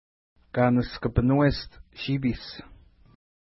Pronunciation: ka:nəskəpinwest ʃi:pi:s
Pronunciation